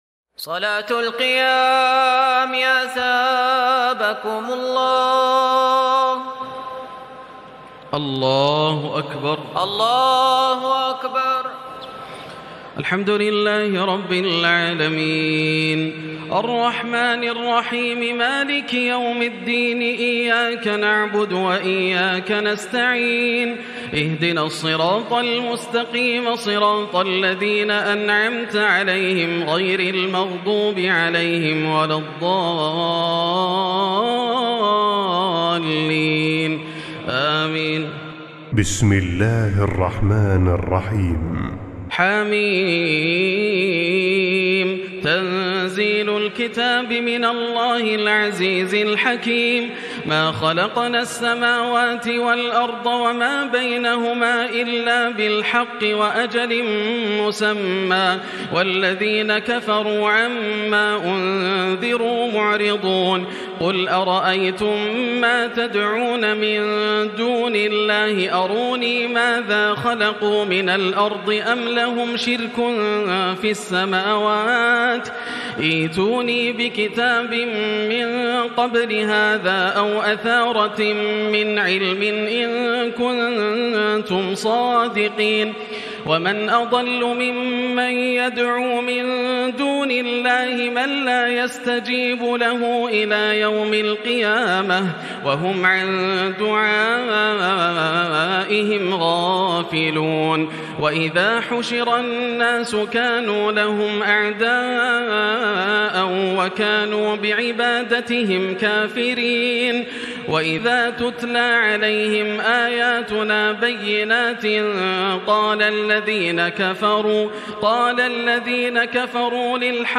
تراويح ليلة 25 رمضان 1440هـ من سور الأحقاف و محمد والفتح 1-17 Taraweeh 25 st night Ramadan 1440H from Surah Al-Ahqaf and Muhammad and Al-Fath > تراويح الحرم المكي عام 1440 🕋 > التراويح - تلاوات الحرمين